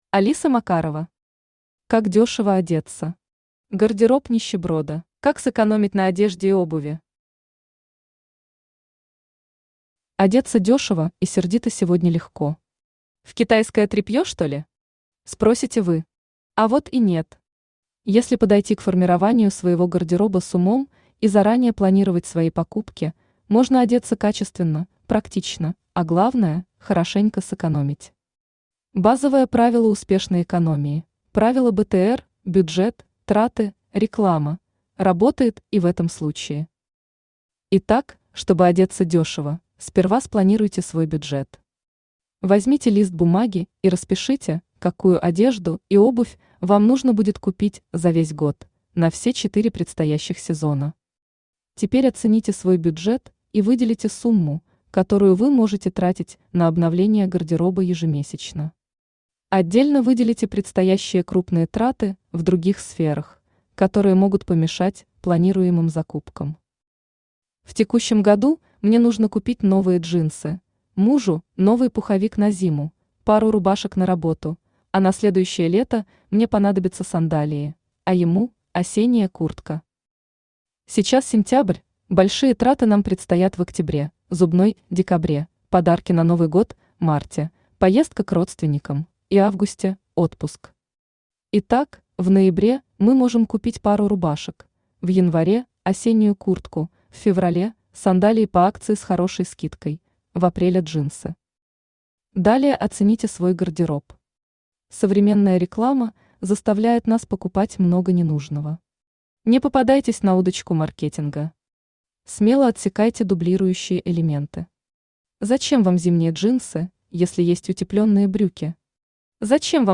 Гардероб нищеброда Автор Алиса Макарова Читает аудиокнигу Авточтец ЛитРес.